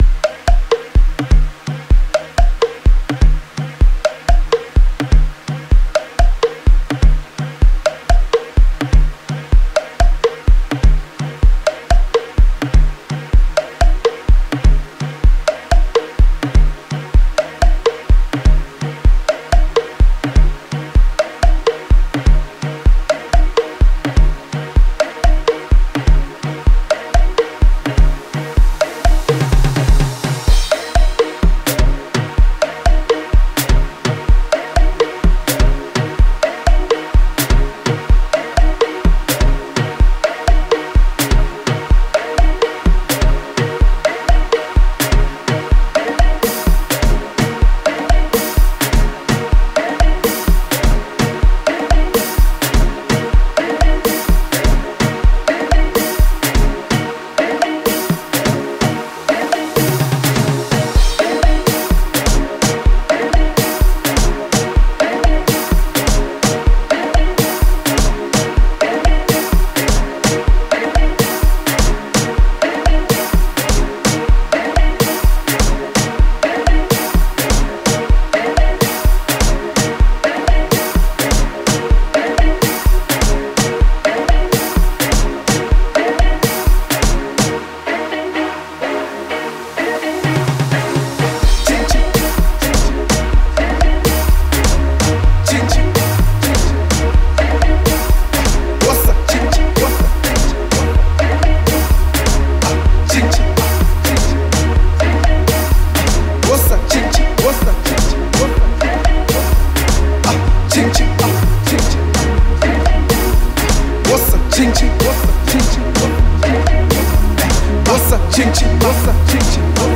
Gqom banger
gqom song